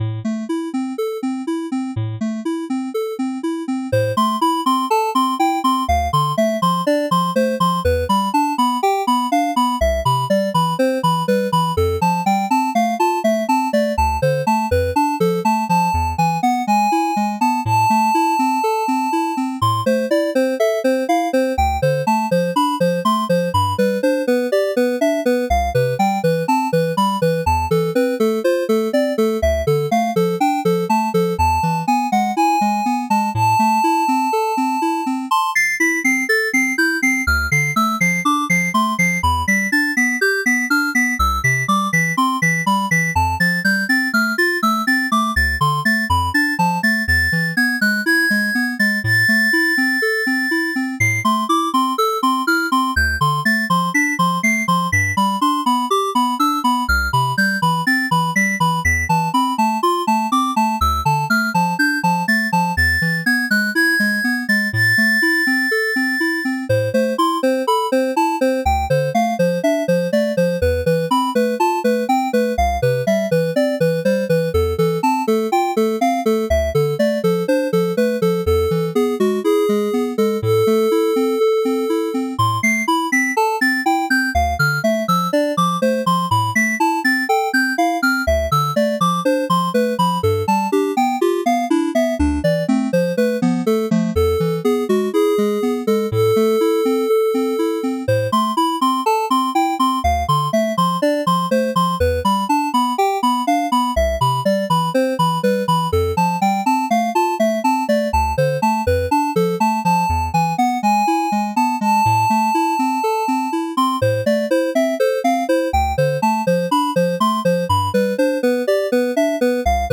Play with default voice  ▶
Beep uses computer generated voices if no voice files exist.